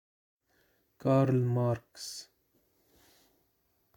العربية: اللفظ العربي لِاسم كارل ماركس. English: Arabic Pronunciation of Karl Marx's name.